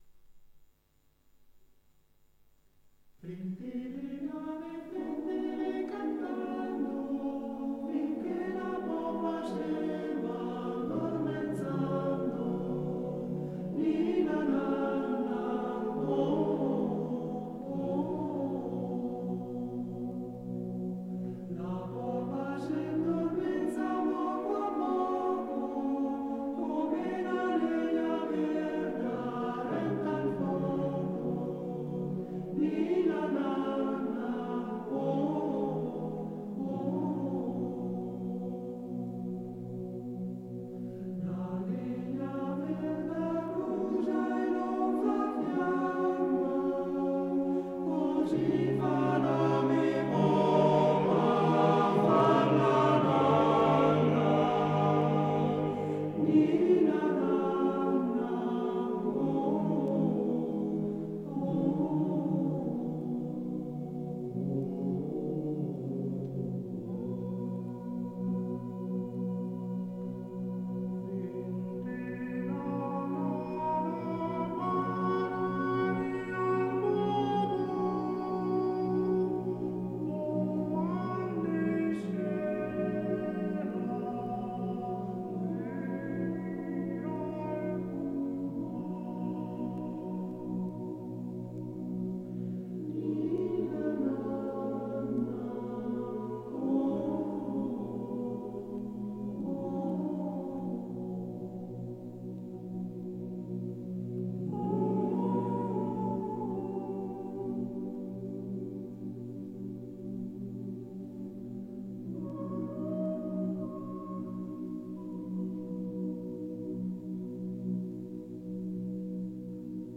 Ninna nanna
Arrangiatore: Dionisi, Renato
Esecutore: Coro della SAT